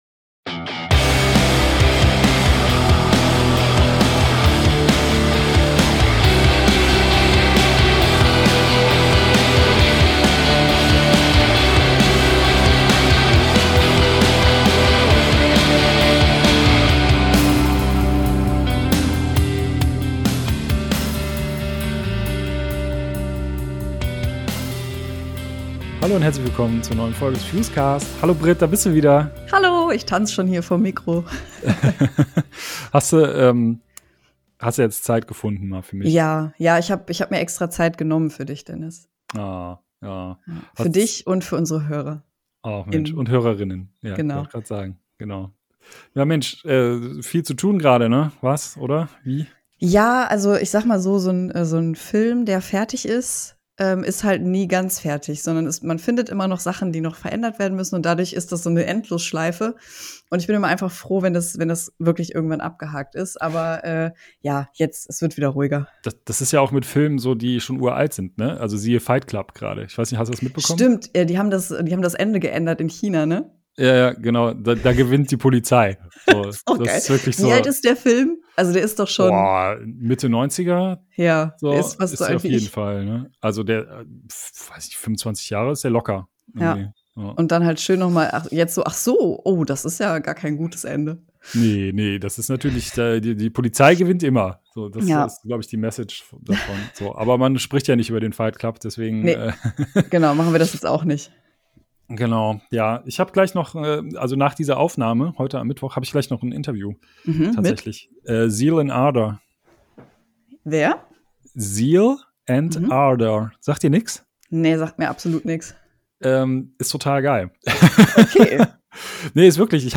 Wenn ihr zum Interview vorspringen wollt, das geht um 00:19:52 los.